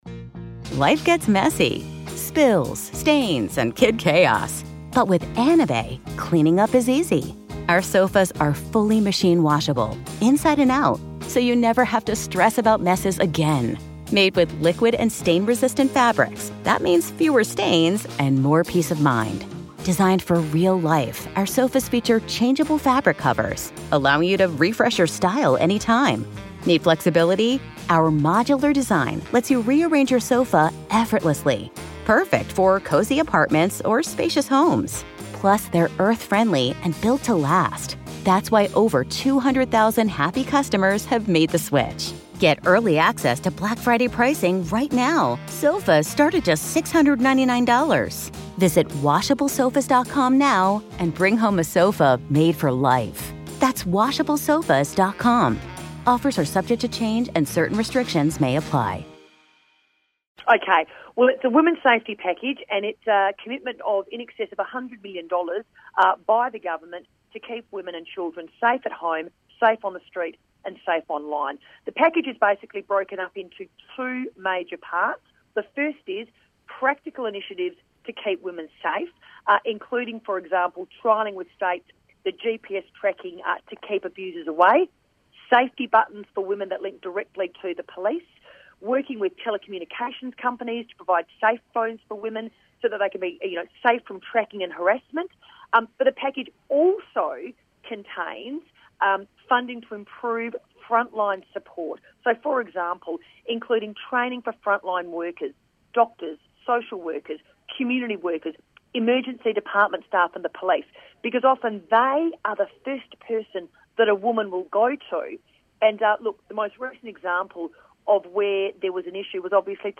Minister for Women Michaelia Cash's interview on 3AW Drive